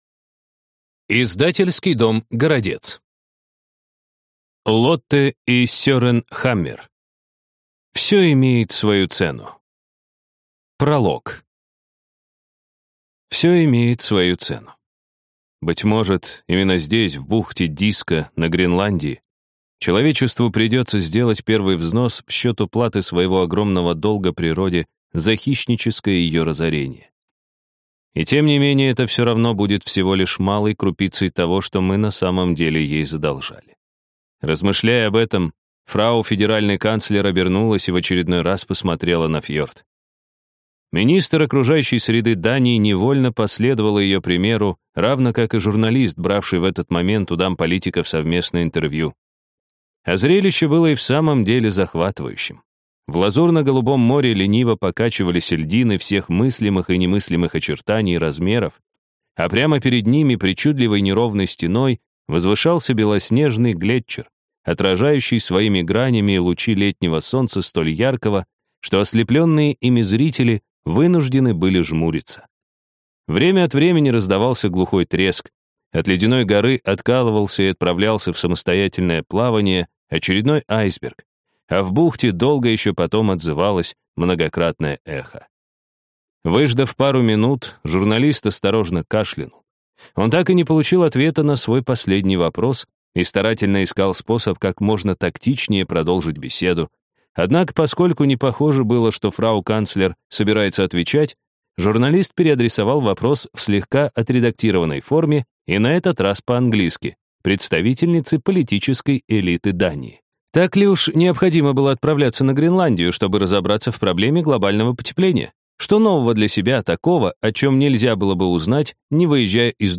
Аудиокнига Всё имеет свою цену | Библиотека аудиокниг